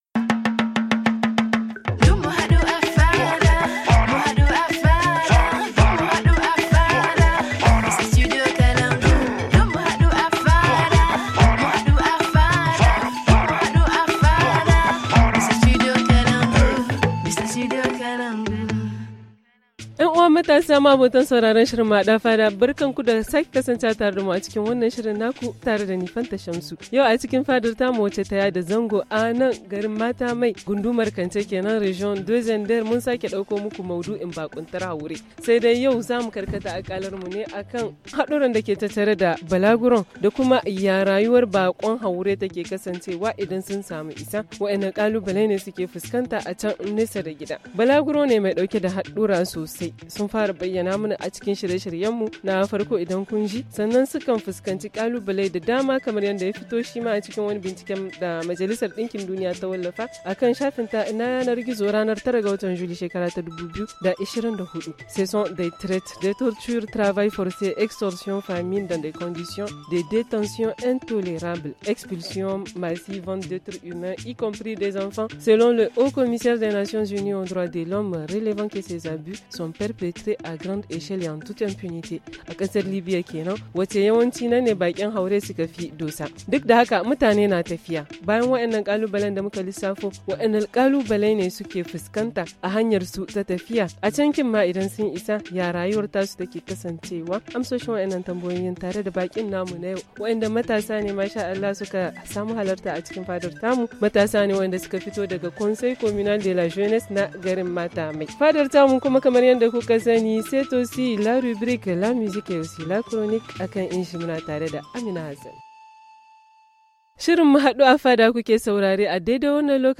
L’émission tous à la fada de ce soir, installée dans la commune urbaine de Matamèye dans le département de Kantché, parle de la migration, précisément, des risques du voyage et des défis de la vie de migrant.
Justement nous sommes avec des ex migrants et futurs candidats pour en parler.